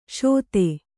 ♪ śote